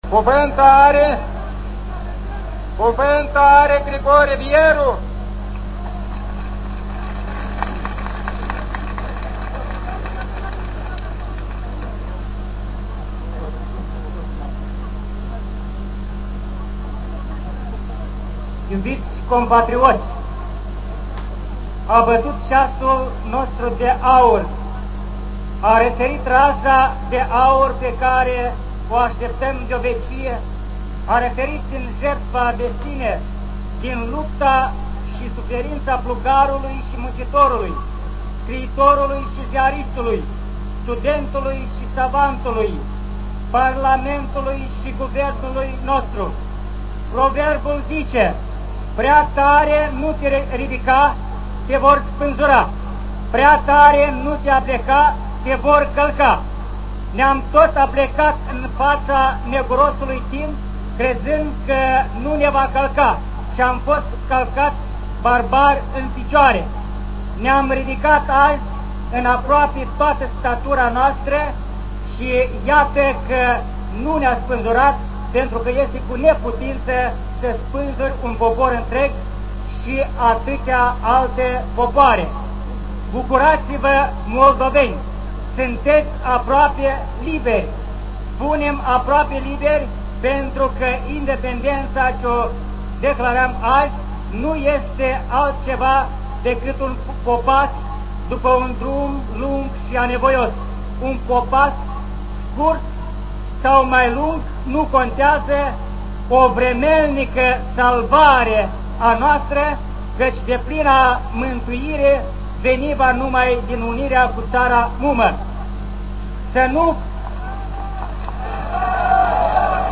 Grigore Vieru was one of the speakers at the Great National Gathering in Chișinău, on the 27th of August 1991, when the Independence of the Republic of Moldova was proclaimed.
a fragment of the great poet and patriot Grigore Vieru's speech, interrupted by ovations for the Union.